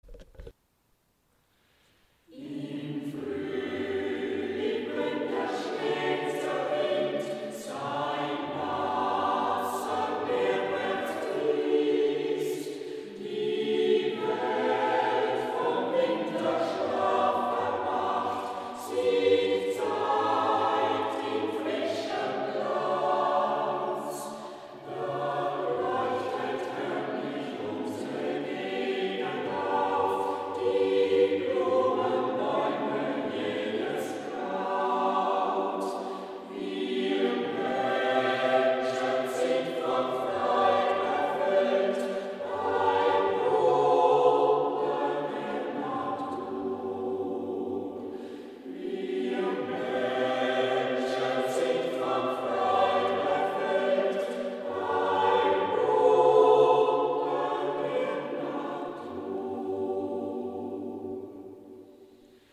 Chor a cappella